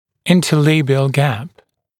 [ˌɪntə’leɪbɪəl gæp][ˌинтэ’лэйбиэл гэп]межгубная щель, расстояние между верхней и нижней губой (обыч. в состоянии покоя)